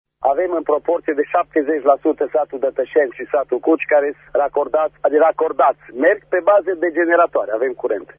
Extras din emisiunea Sens Unic